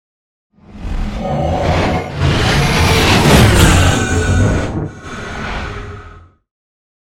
Scifi whoosh pass by long
Sound Effects
Atonal
No
futuristic
tension
the trailer effect